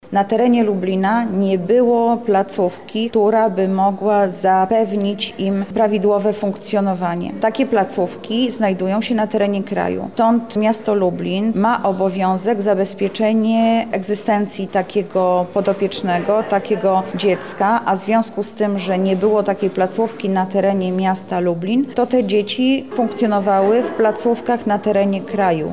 – mówi Anna Augustyniak, Zastępca Prezydenta Miasta Lublin ds. Społecznych